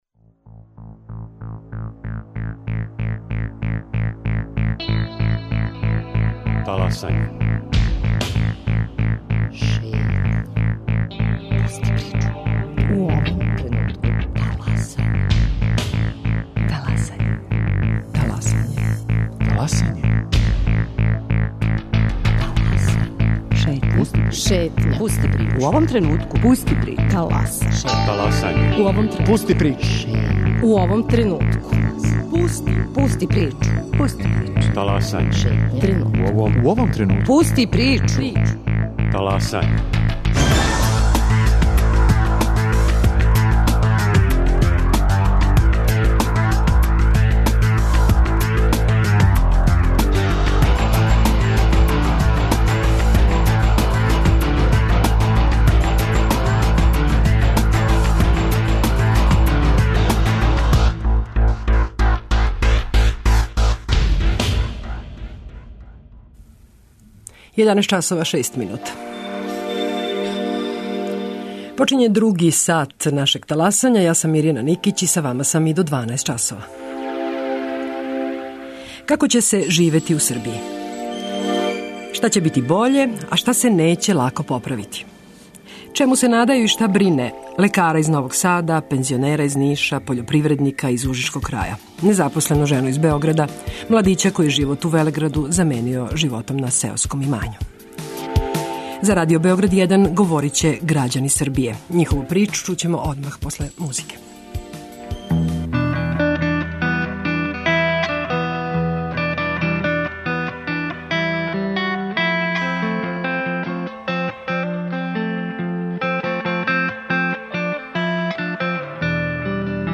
Чему се надају и шта брине: лекара из Новог Сада, пензионера из Ниша, пољопривредника из ужичког краја, незапослену жену из Београда, младића који је живот у велеграду заменио за сеоско имање.
За Радио Београд 1 говоре грађани Србије.